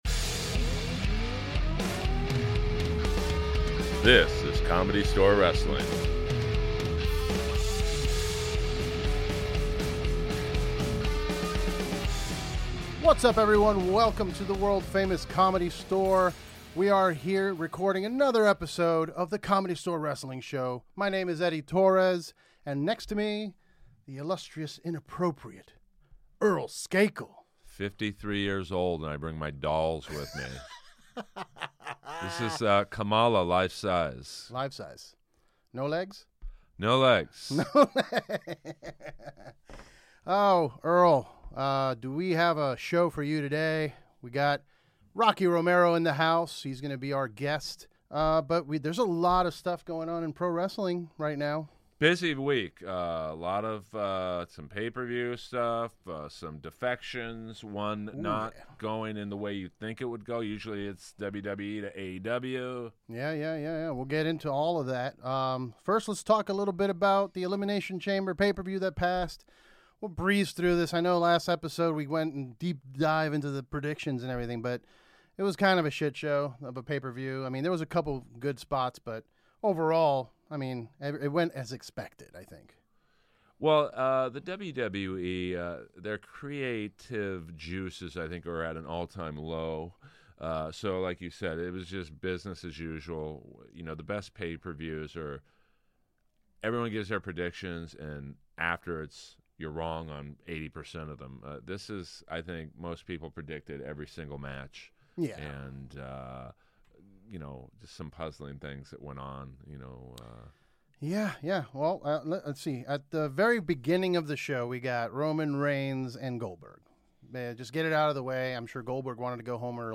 PLUS badass NJPW star Rocky Romero is in-studio to chat all things wrestling.